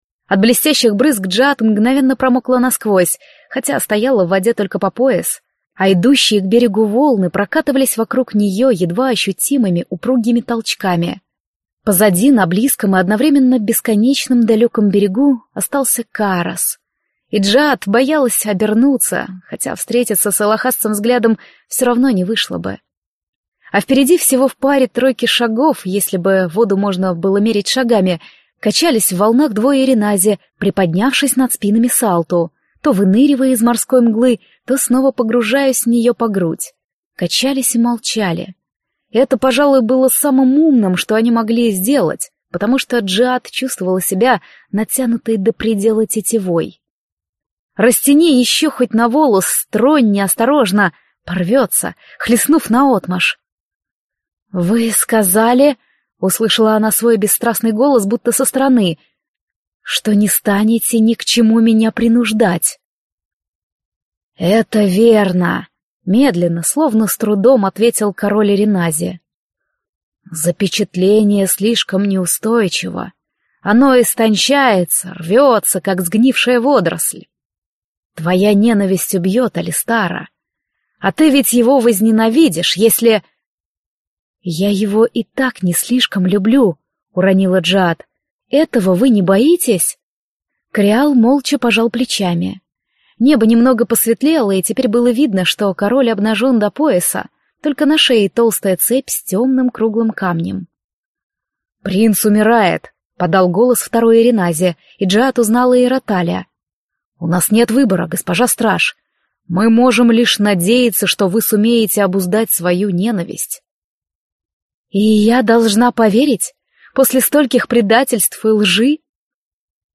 Аудиокнига Избранная морского принца | Библиотека аудиокниг